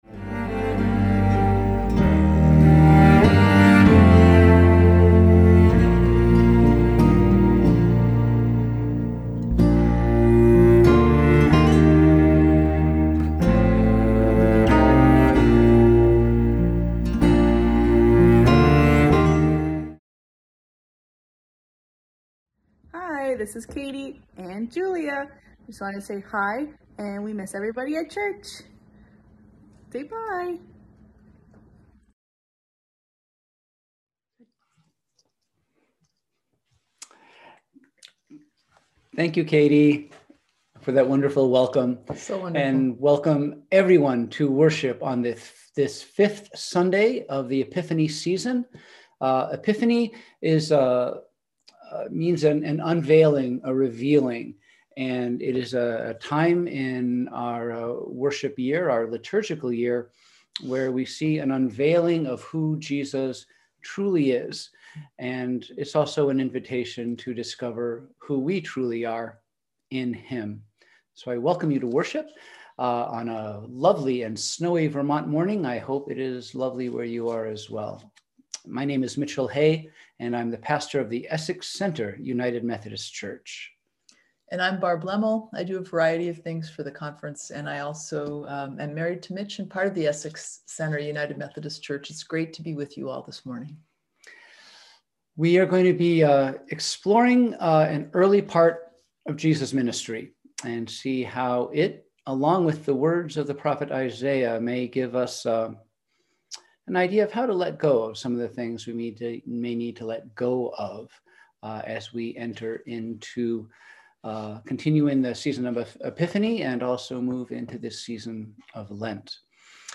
We held virtual worship on Sunday, February 7, 2021 at 10:00am.